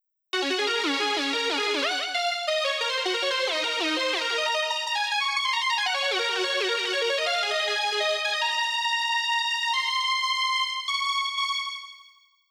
Hardcore
リード部分のソロがこちら。
暴れてますねぇ～
A♯マイナーキーとなっています。